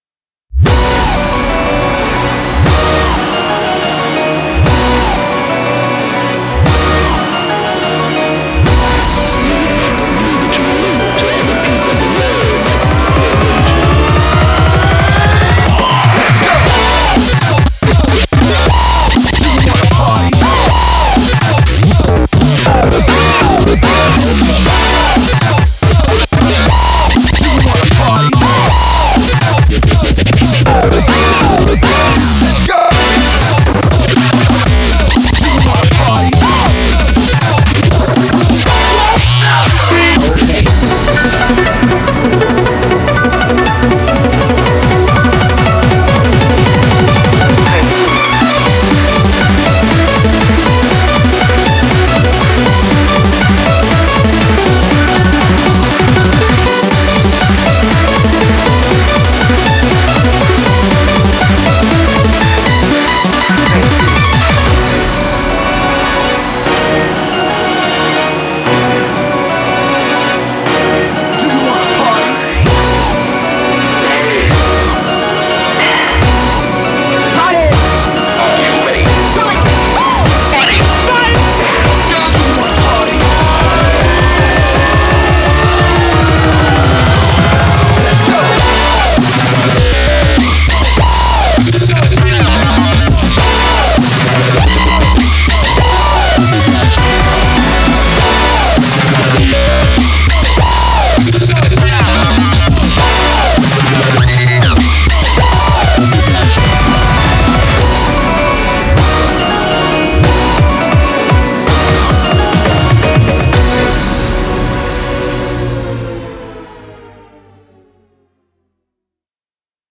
BPM120-400